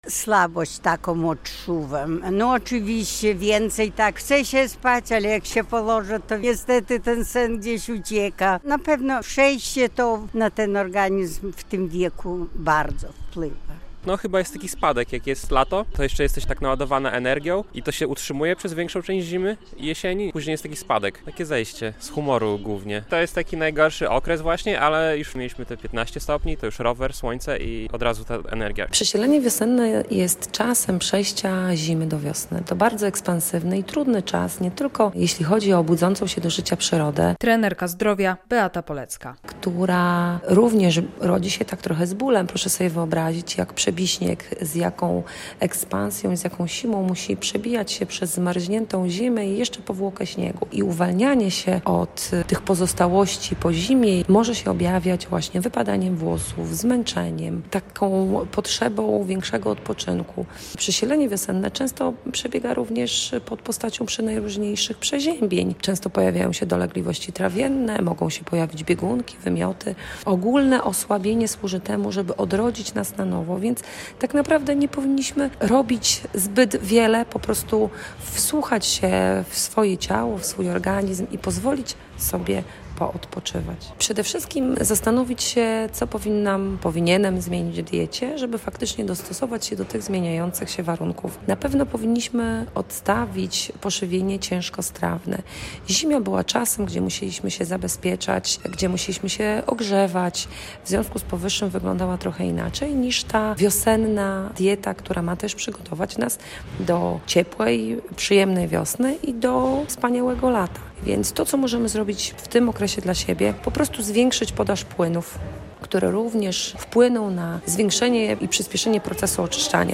Przesilenie wiosenne - relacja